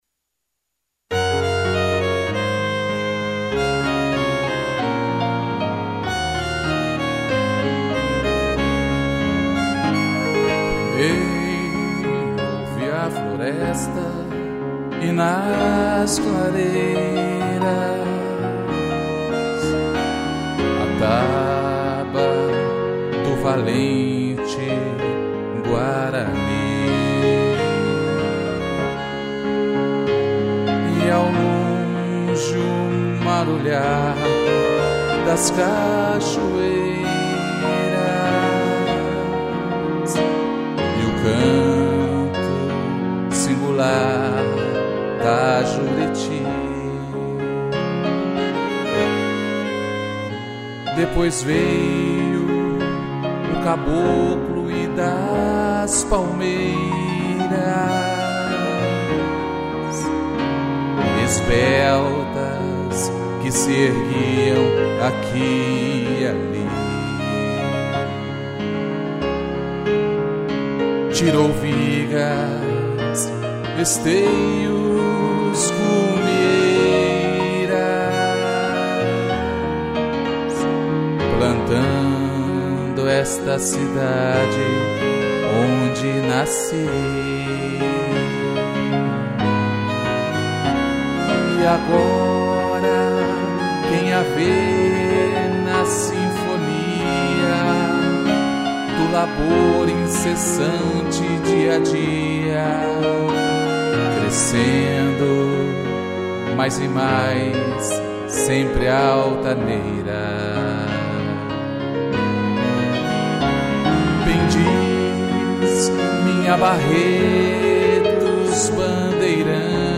2 pianos, violino e cello